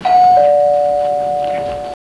porte.aiff